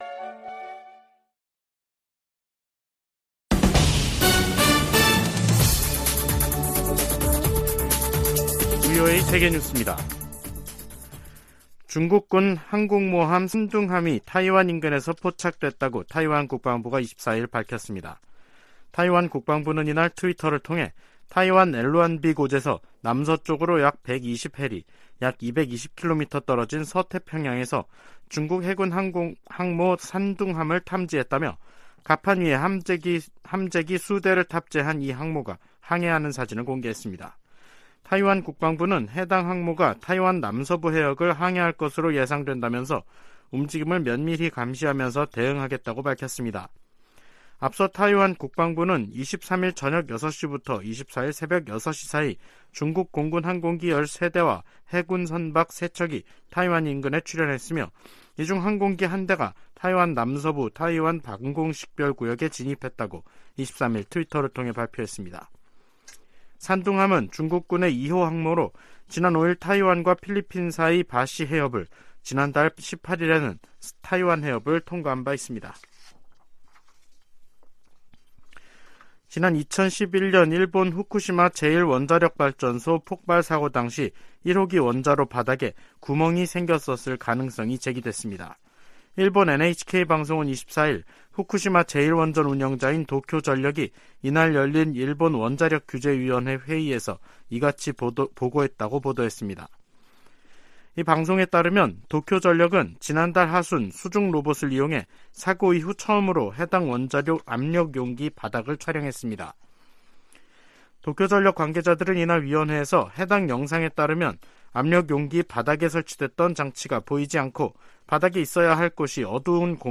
VOA 한국어 간판 뉴스 프로그램 '뉴스 투데이', 2023년 4월 24일 3부 방송입니다. 백악관은 윤석열 한국 대통령의 국빈 방문이 미한 관계의 중요성을 증명하는 것이라고 강조했습니다. 윤 대통령은 방미를 앞두고 워싱턴포스트 인터뷰에서 미한동맹의 중요성을 거듭 강조했습니다. 미 국무부가 핵보유국 지위와 관련한 북한의 주장에 대해 불안정을 조성하는 행동을 자제하고 협상에 복귀할 것을 촉구했습니다.